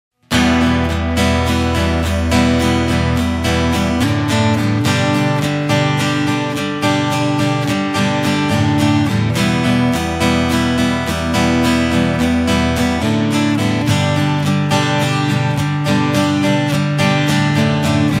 Интересует, объемность звучания гитары, преобладание низов, ярко выраженный звук гитары, громкость.